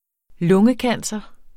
Udtale [ ˈlɔŋə- ]